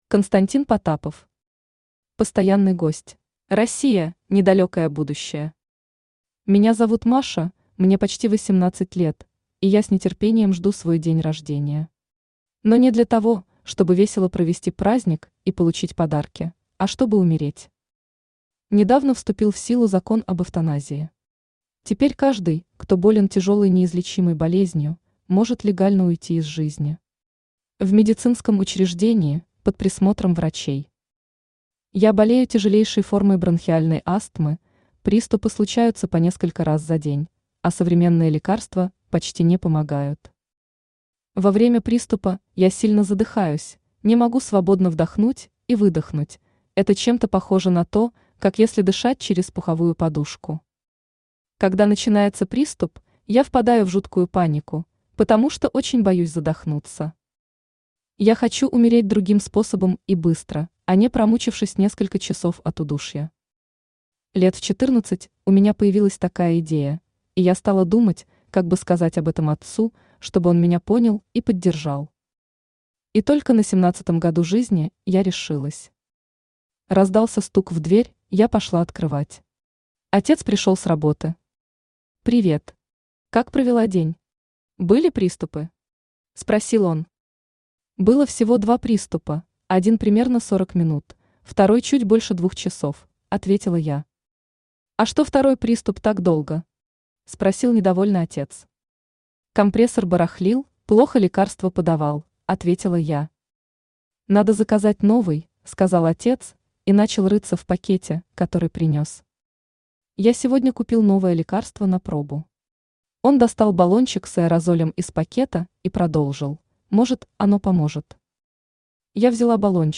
Аудиокнига Эвтаназия | Библиотека аудиокниг
Aудиокнига Эвтаназия Автор Константин Потапов Читает аудиокнигу Авточтец ЛитРес.